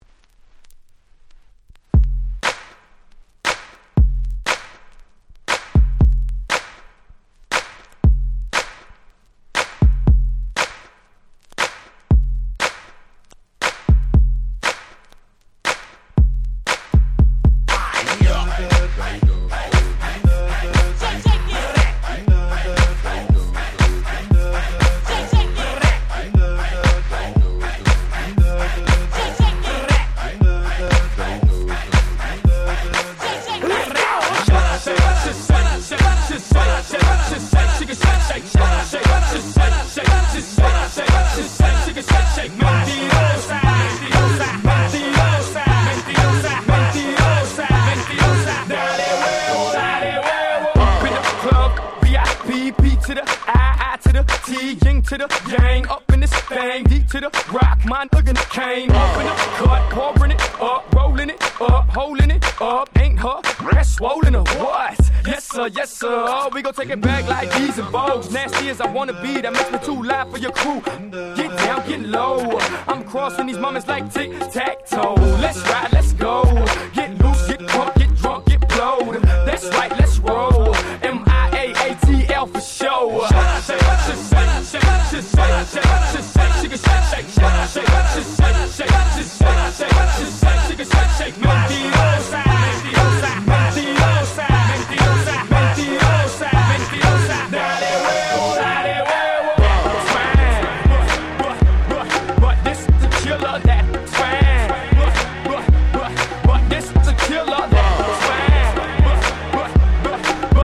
【Media】Vinyl 12'' Single
自分が当時好んで使用していた曲を試聴ファイルとして録音しておきました。